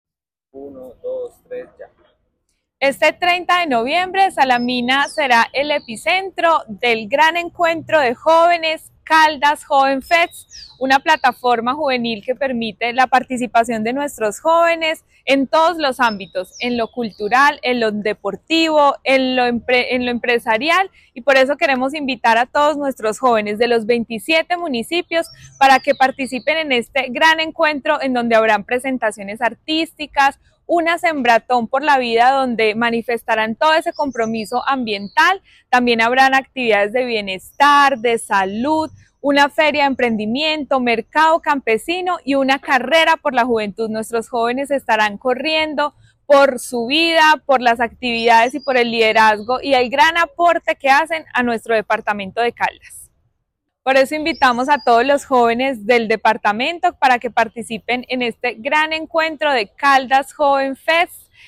Daissy Lorena Alzate, secretaria de Desarrollo, Empleo e Innovación de Caldas.
Daissy-Lorena-Alzate-secretaria-de-Desarrollo-Empleo-e-Innovacion-de-Caldas.-.mp3